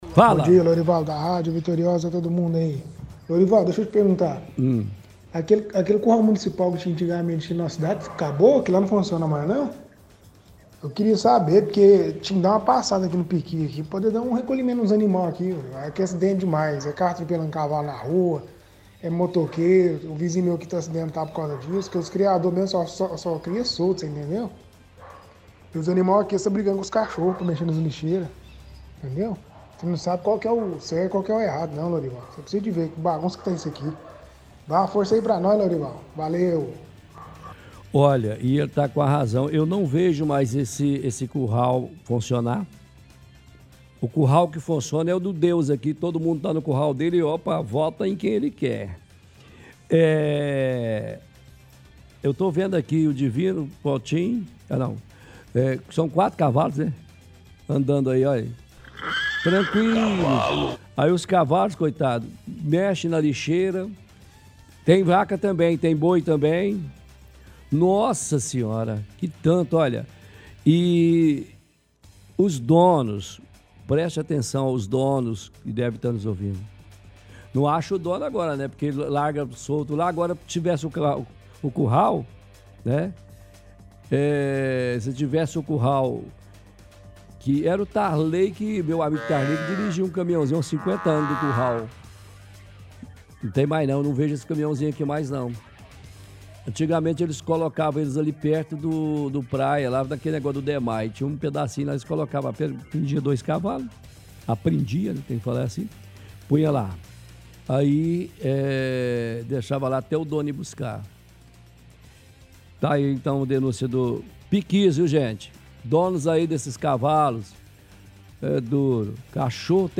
– Ouvinte questiona se não tem mais o serviço de recolhimento de animais soltos pois perto da casa dele muitos cavalos no meio da rua causam acidentes.